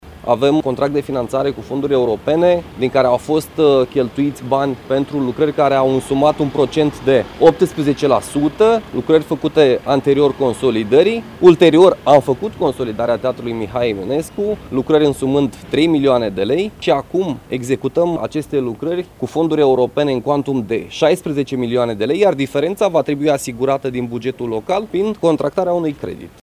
Cosmin Andrei, primarul municipiului Botoșani: